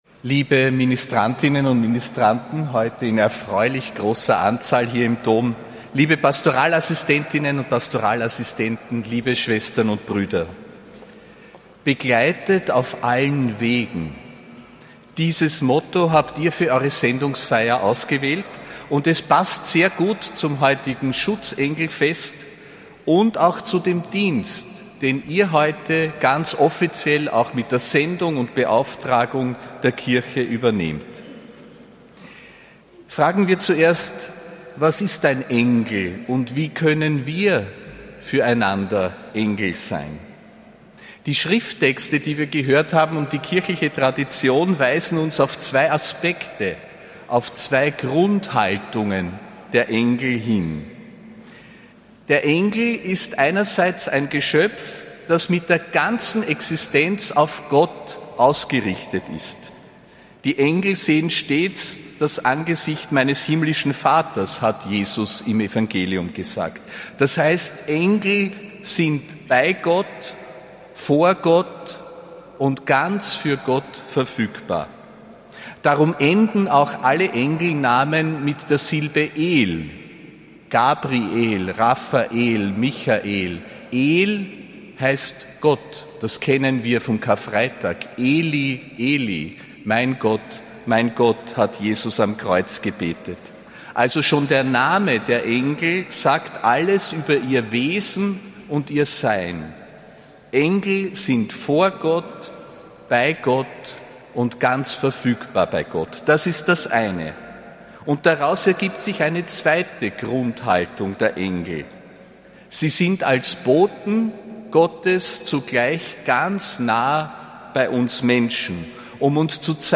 Predigt von Josef Grünwidl bei der Sendungsfeier der Patoralassistentinnen und Pastoralassistenten (2. Oktober 2025)